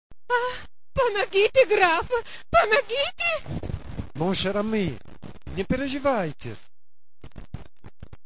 5 Giugno 1999, Cortile di Palazzo Rinuccini
Adattamento scenico in lingua originale del racconto di Puskin ad opera dei partecipanti al primo corso di teatro